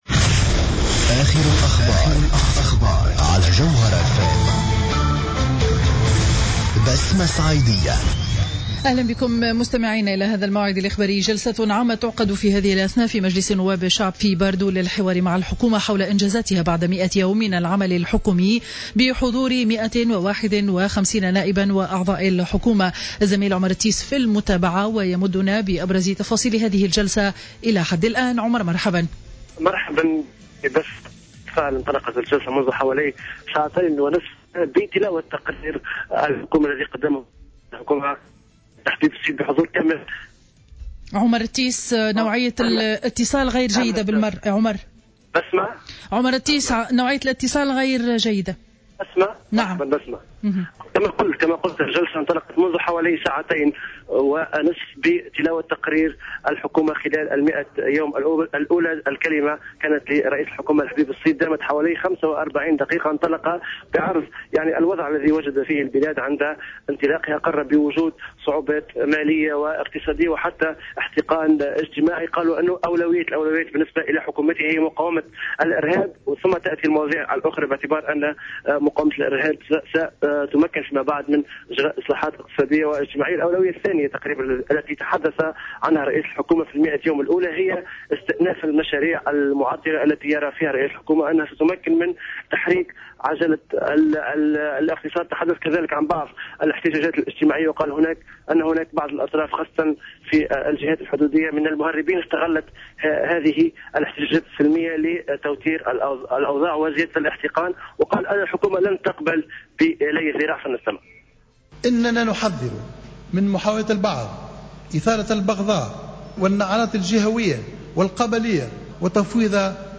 نشرة الأخبار منتصف النهار ليوم الجمعة 5 جوان 2015